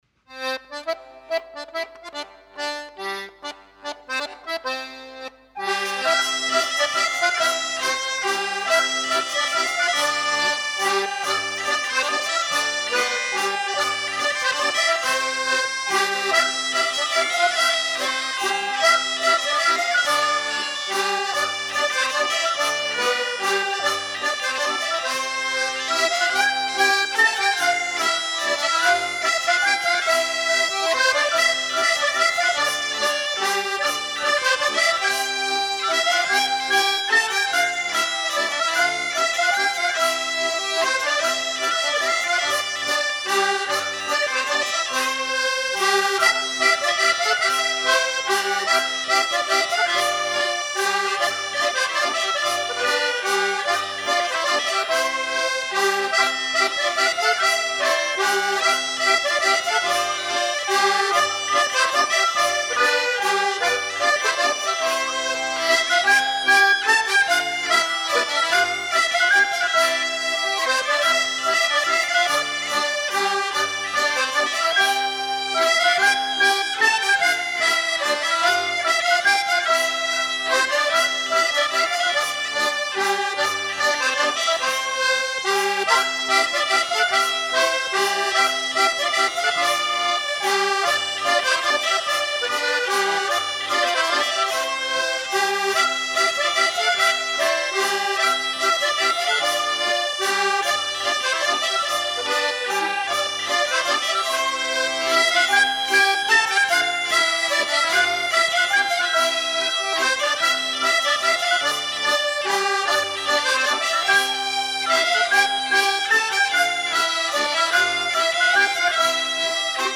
mazurka
jouée par les ménétriers